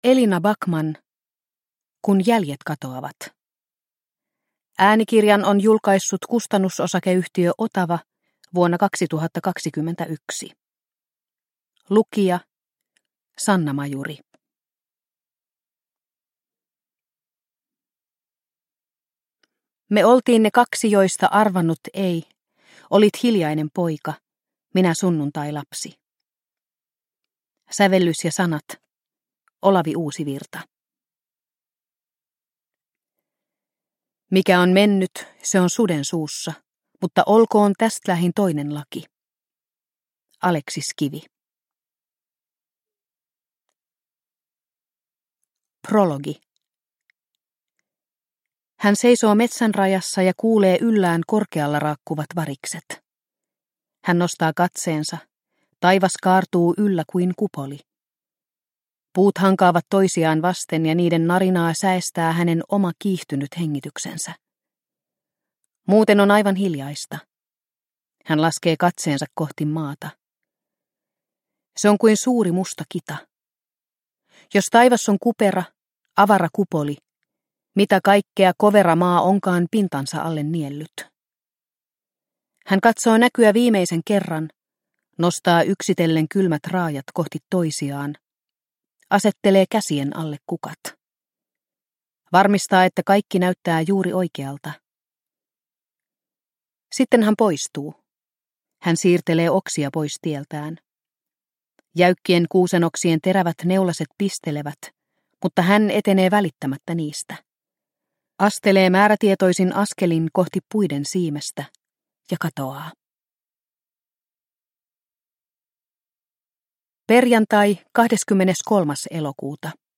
Kun jäljet katoavat – Ljudbok – Laddas ner